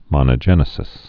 (mŏnə-jĕnĭ-sĭs)